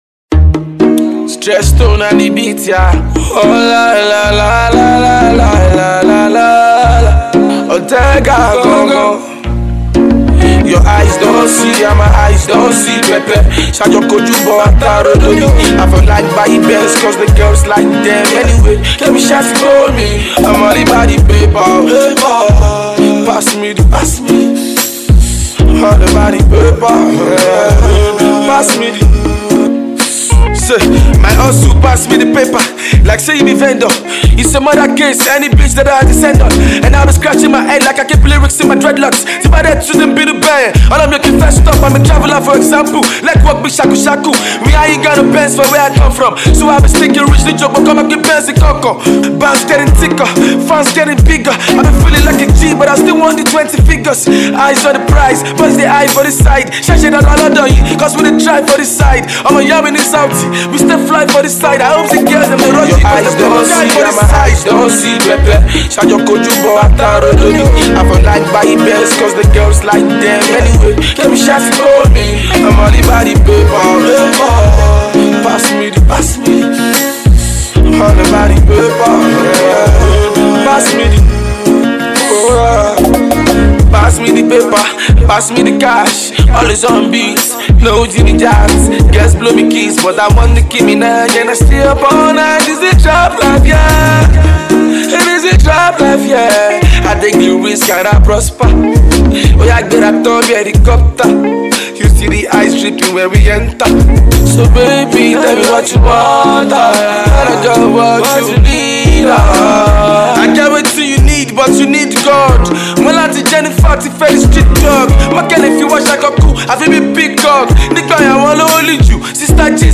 Talented Nigerian hip-hop sensation